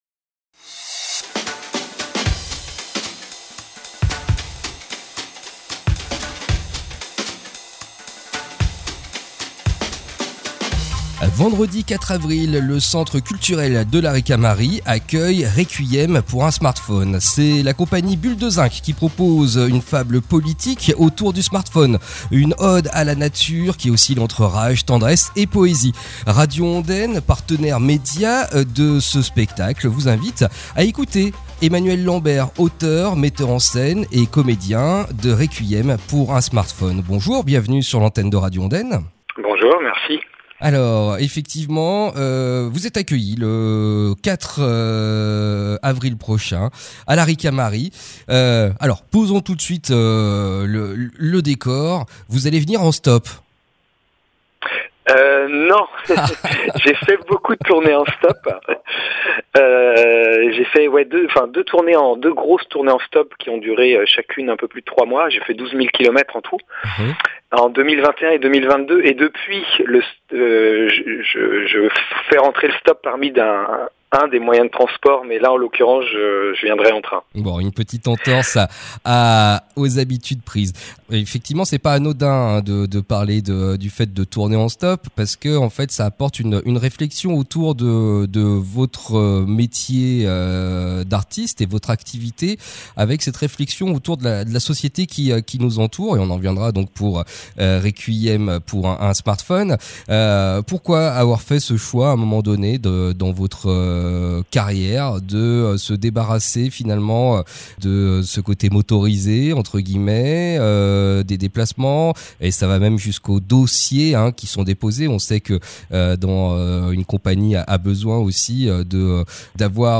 une discussion